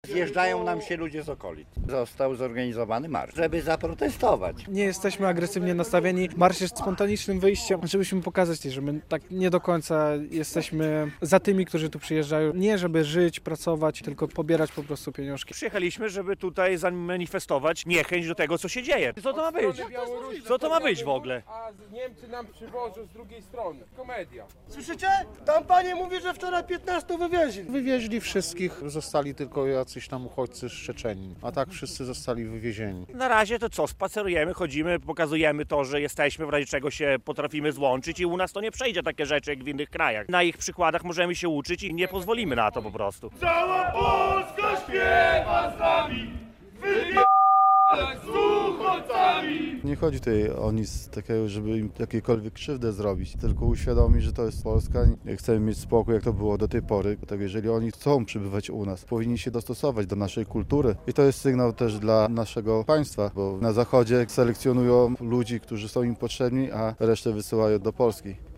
Protest przed ośrodkiem dla cudzoziemców w Czerwonym Borze - relacja